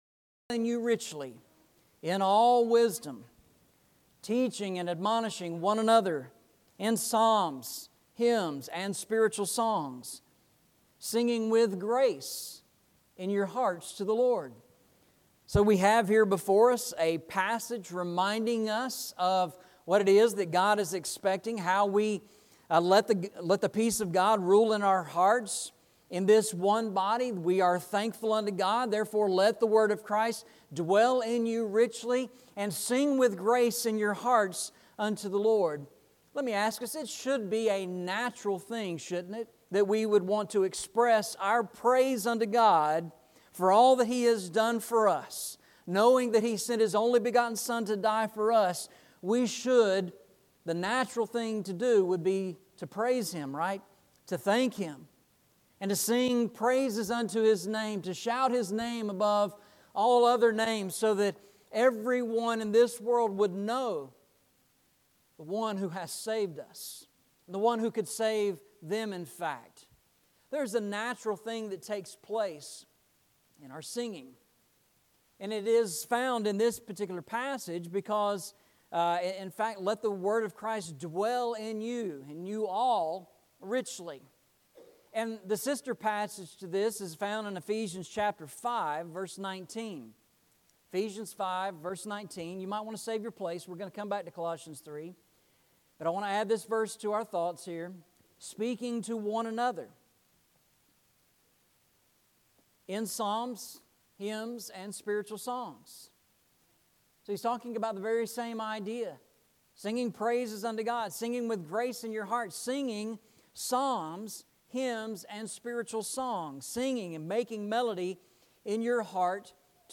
Worship in Song